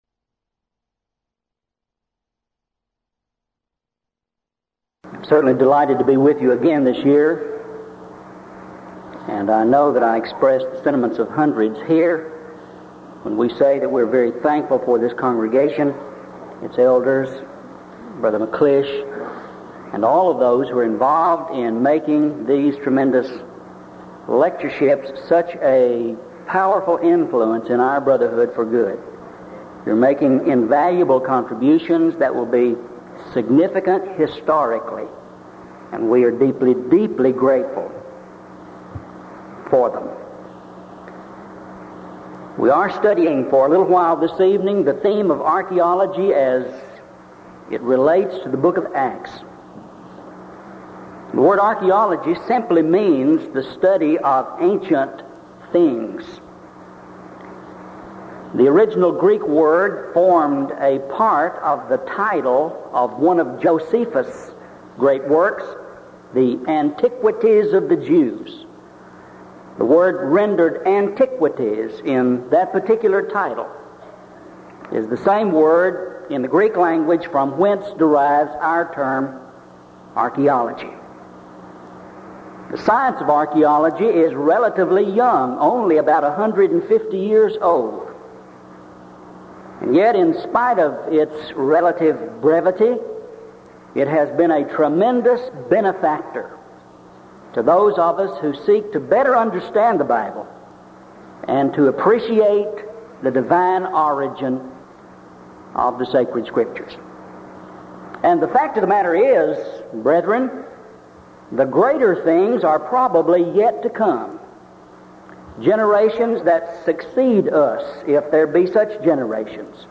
Event: 1985 Denton Lectures
lecture